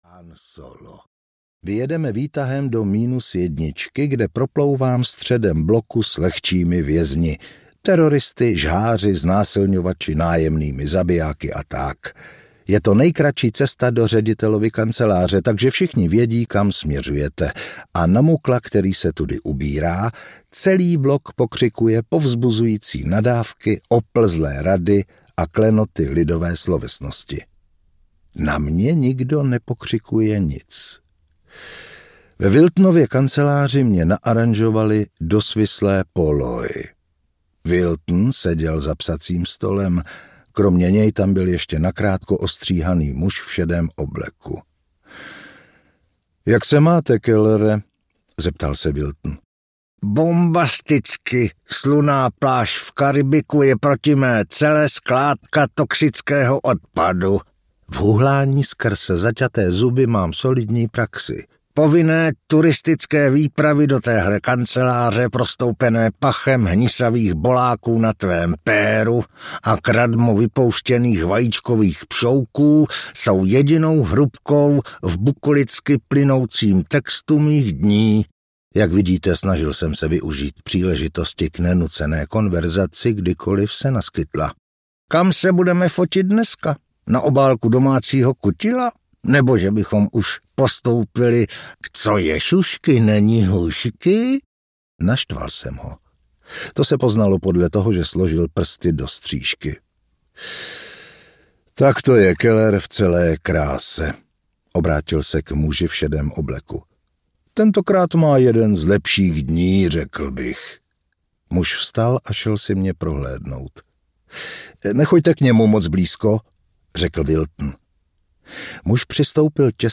Zabíjení audiokniha
Ukázka z knihy